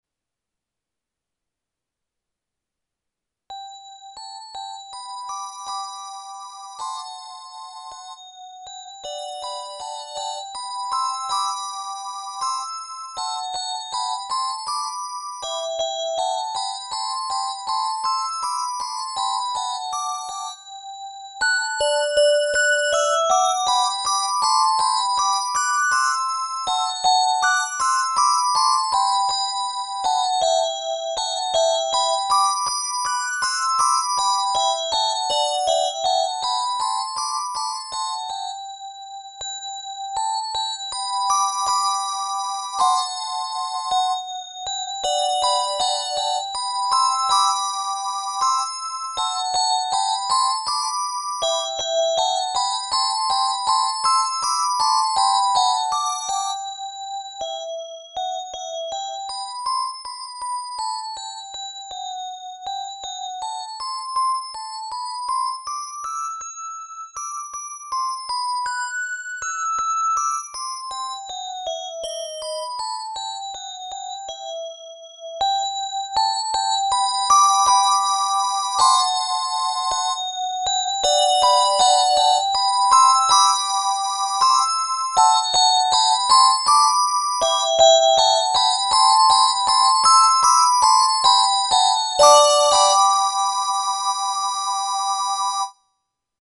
Key of G Major.